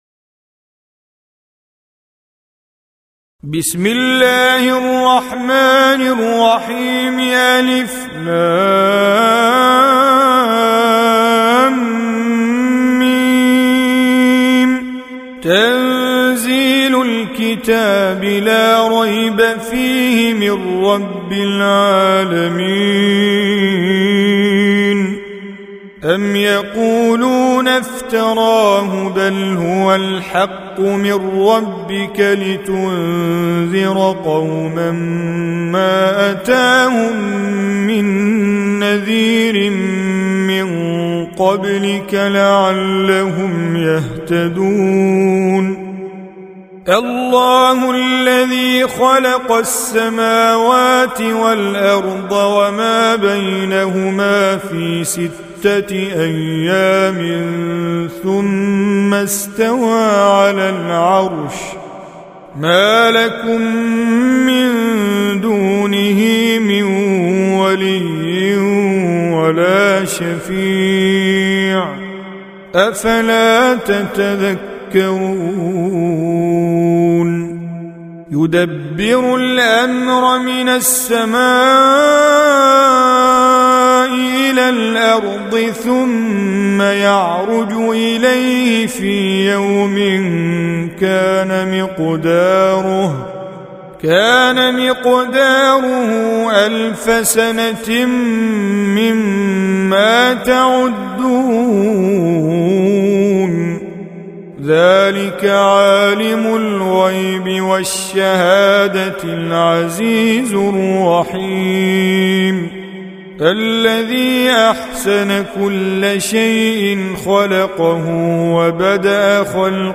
Surah Repeating تكرار السورة Download Surah حمّل السورة Reciting Mujawwadah Audio for 32. Surah As�Sajdah سورة السجدة N.B *Surah Includes Al-Basmalah Reciters Sequents تتابع التلاوات Reciters Repeats تكرار التلاوات